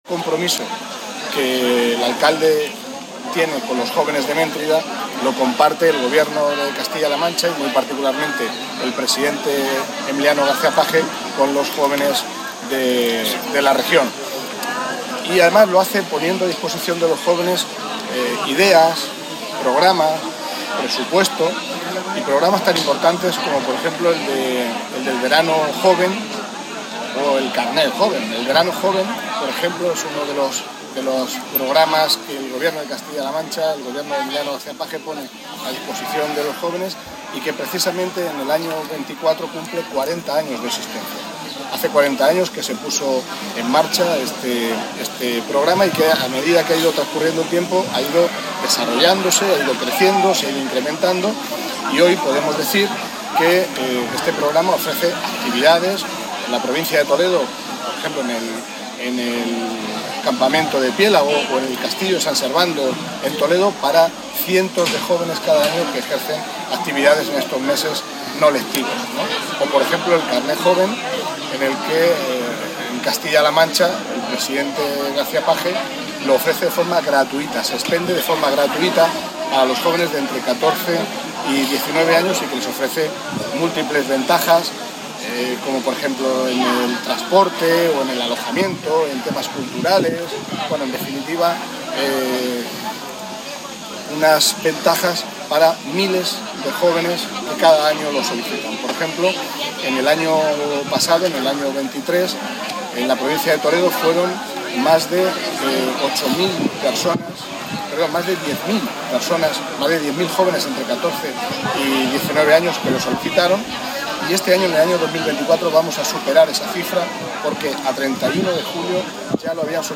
corte_mentrida_centrojuvenil_inauguracion_delegadojuntatoledo.mp3